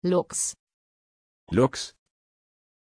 Aussprache von Lux
pronunciation-lux-de.mp3